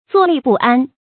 zuò lì bù ān
坐立不安发音
成语注音ㄗㄨㄛˋ ㄌㄧˋ ㄅㄨˋ ㄢ